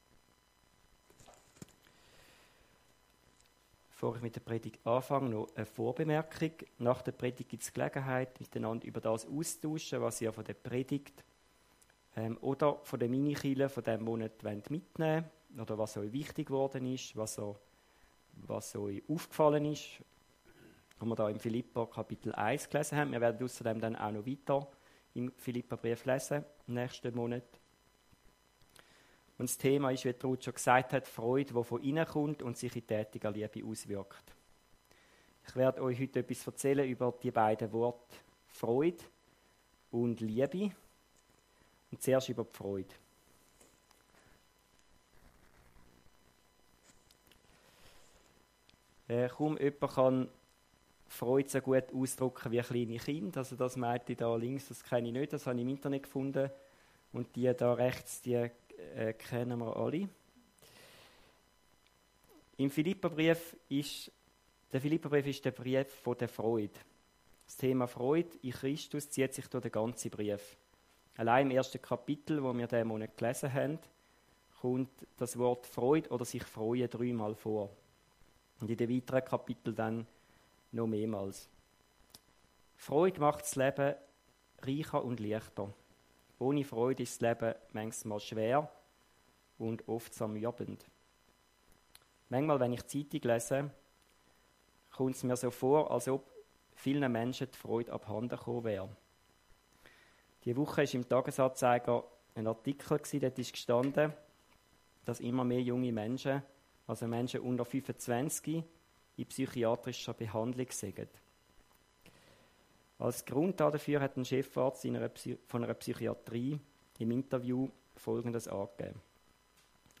Predigt über Philipperbrief, Kapitel 1, Vers 9